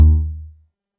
Hirameki-SRS/assets/sfx/incorrect.wav at 16da0f04ac83c9c553ae0b6dcb53ae5c47296d6e
incorrect.wav